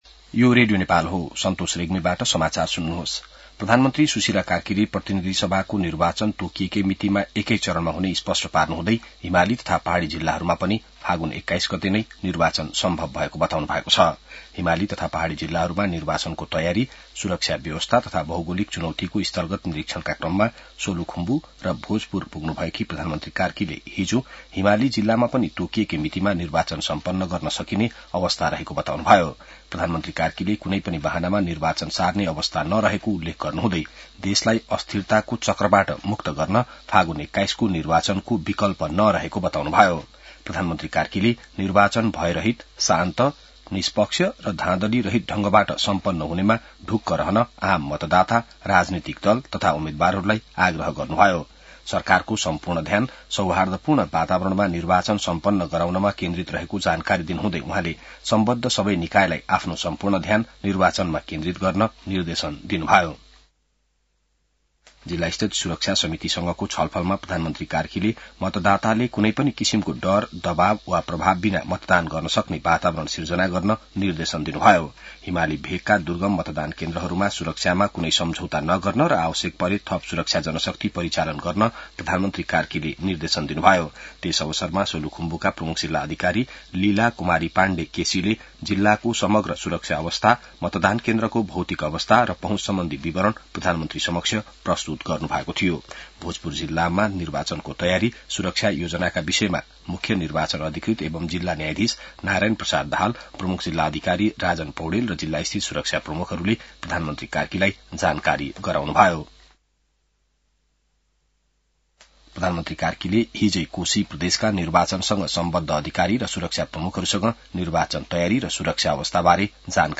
बिहान ६ बजेको नेपाली समाचार : १ फागुन , २०८२